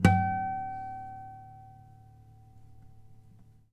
harmonic-04.wav